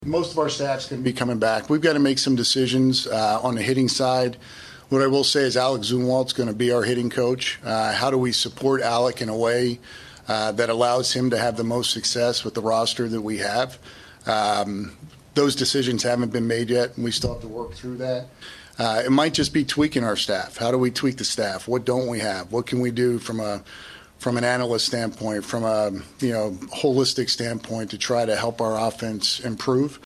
ROYALS HOLD END OF SEASON PRESS CONFERENCE